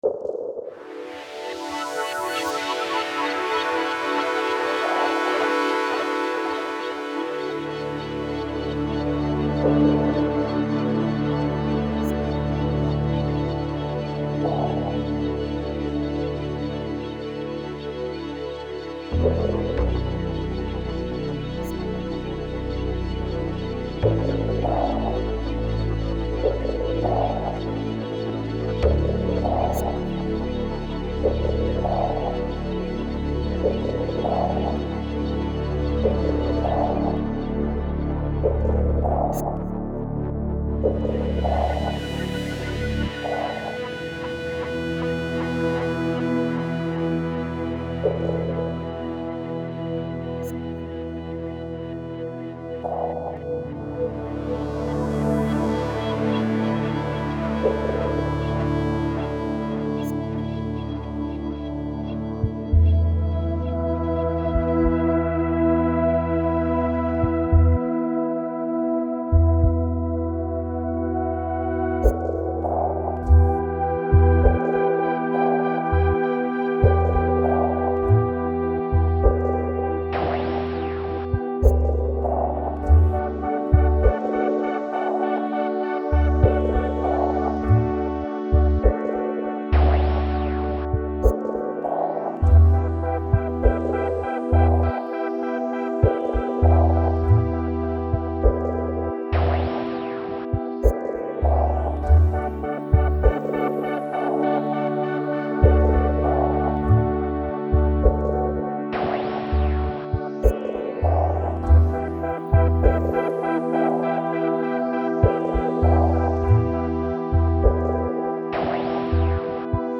A couple of years old, made entirely in Reason. A slow-moving track, cinematic though with no epic glory to it. More a silent afterthought, the 2nd part rhythmic including samples of a beating heart.